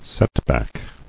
[set·back]